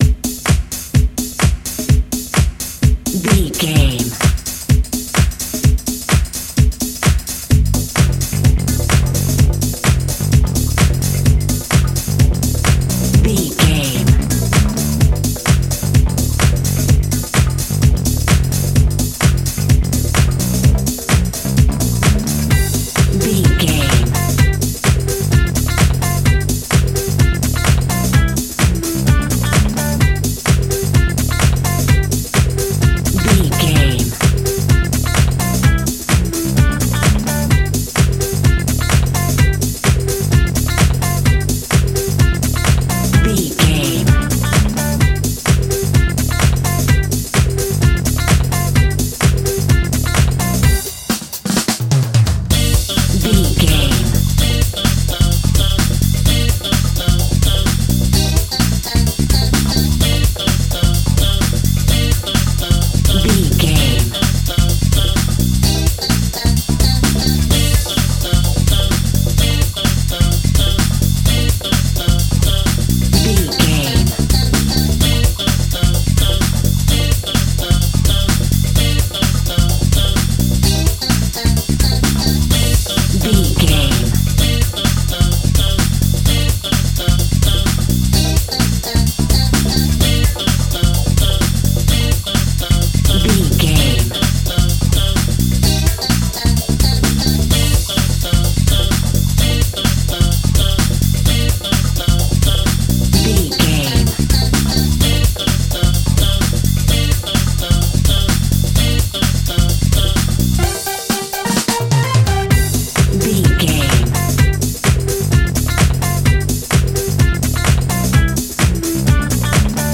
Aeolian/Minor
funky
groovy
uplifting
driving
energetic
bass guitar
electric guitar
drum machine
synthesiser
electric piano
funky house
disco house
electronic funk
upbeat
Synth pads
clavinet
horns